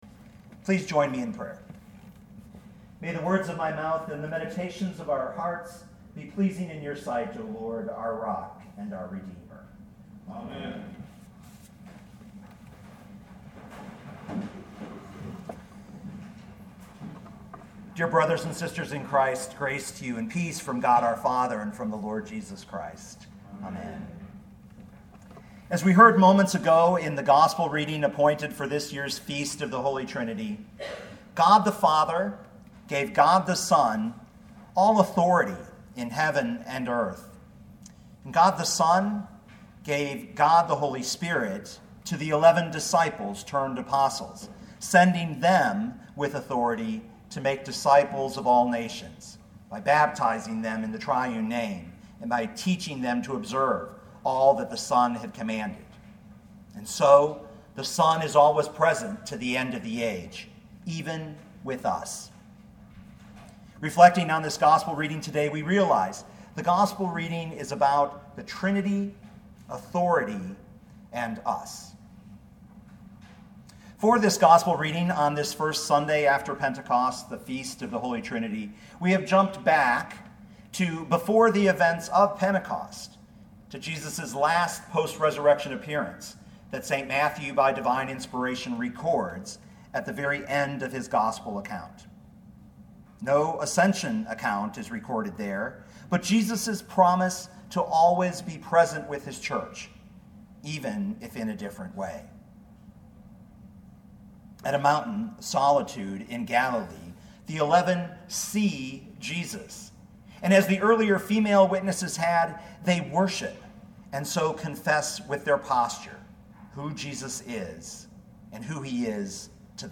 2017 Matthew 28:16-20 Listen to the sermon with the player below, or, download the audio.